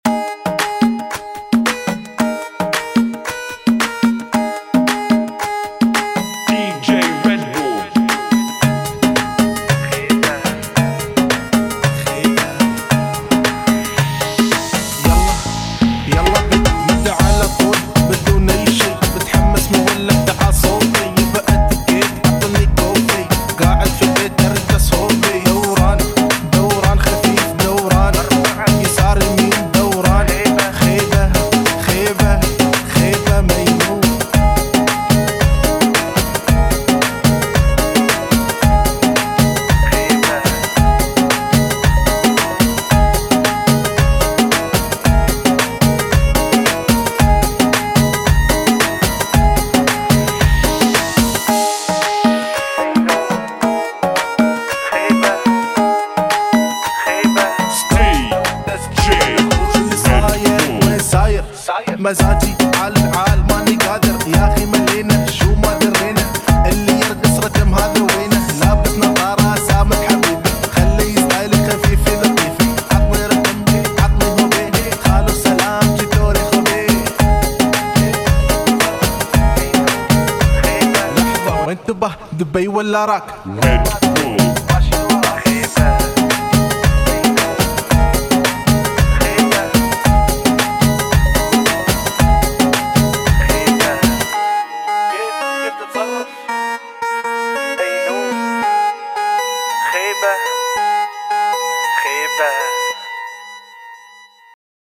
[ 112 bpm ]